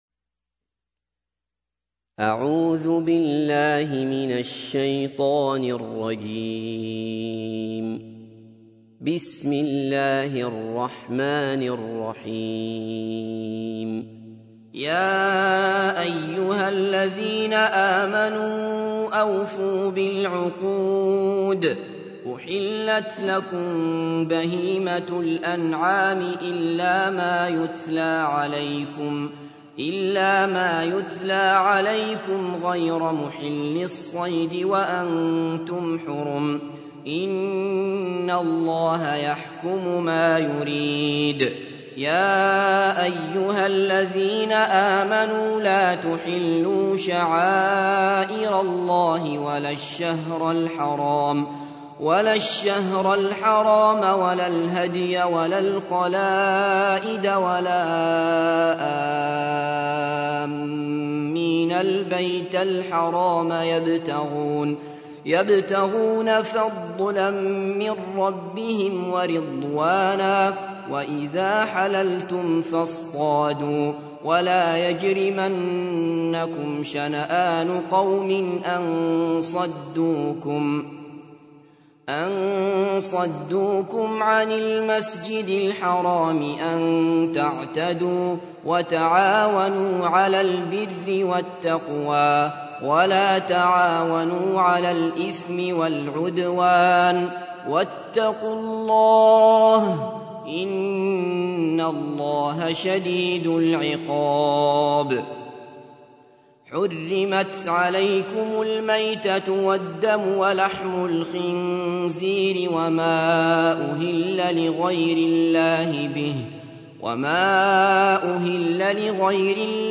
تحميل : 5. سورة المائدة / القارئ عبد الله بصفر / القرآن الكريم / موقع يا حسين